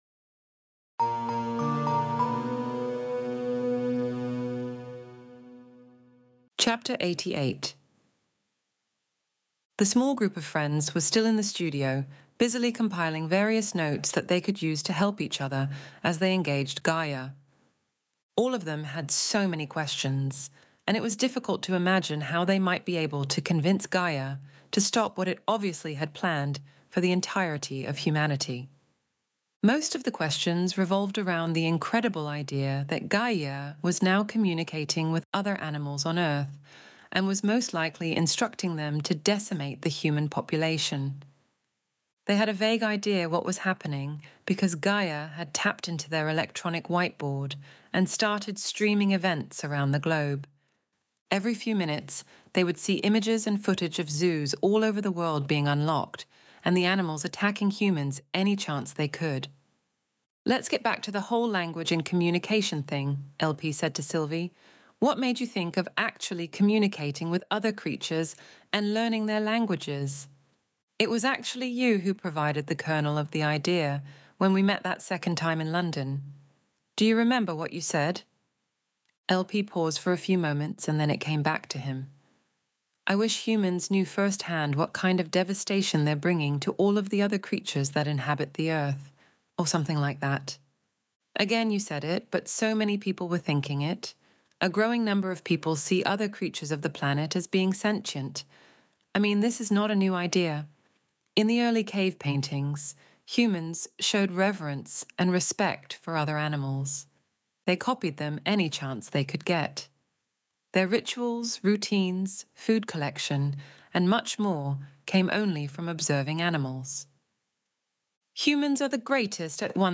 Extinction Event Audiobook Chapter 88